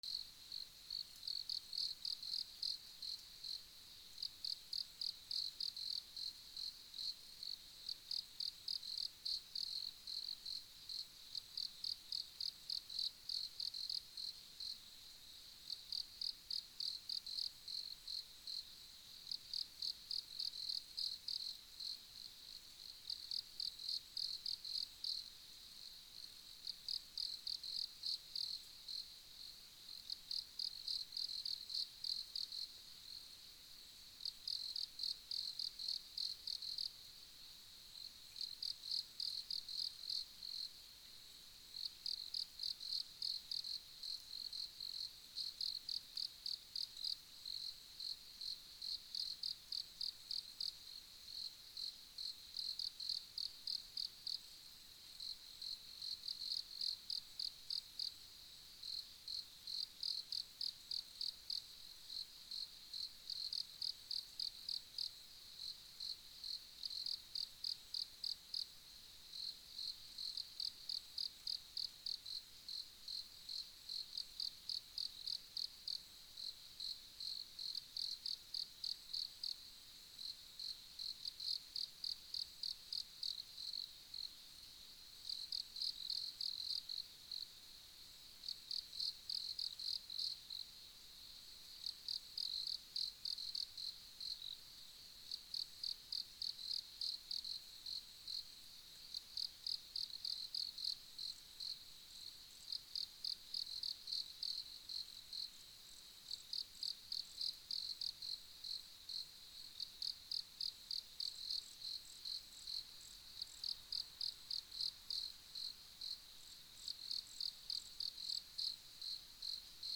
虫の鳴き声(夜) 虫少なめ
/ B｜環境音(自然) / B-30 ｜虫の鳴き声 / 虫20_虫の鳴き声20_山・森林
原谷 京都府 10月後半 DR100